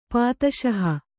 ♪ pātaṣaha